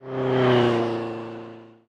CosmicRageSounds / ogg / general / highway / oldcar / car7.ogg
car7.ogg